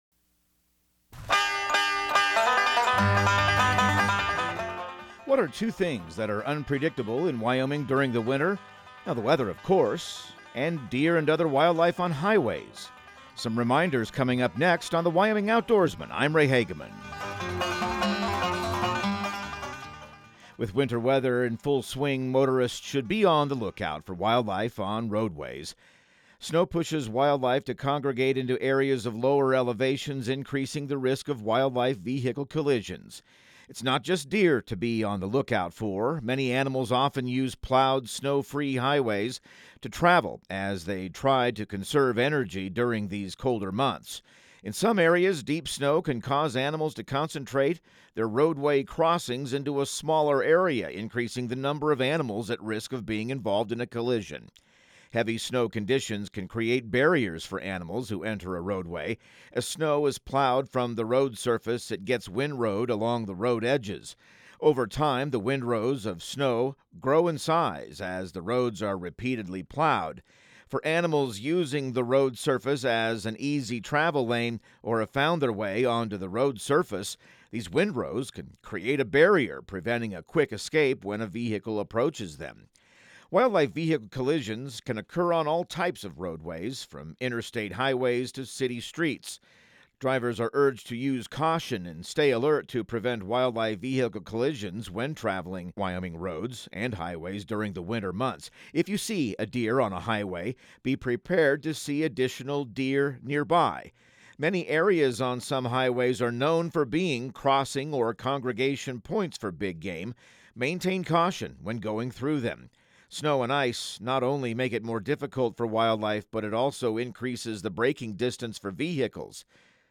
Radio news | Week of February 24